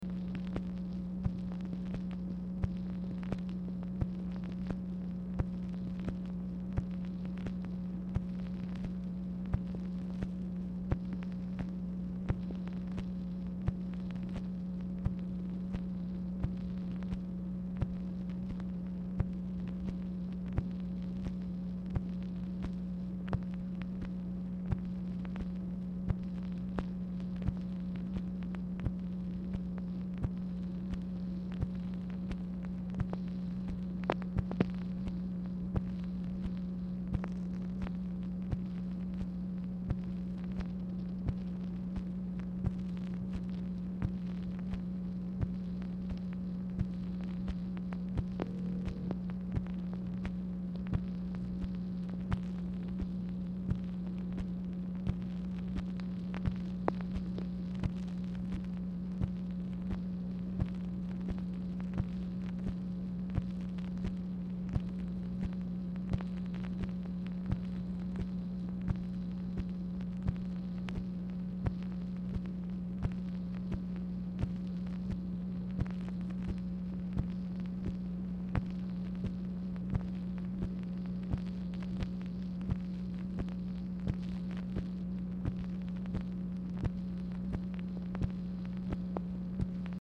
Telephone conversation # 10244, sound recording, MACHINE NOISE, 6/17/1966, time unknown | Discover LBJ
Telephone conversation # 10244
Dictation belt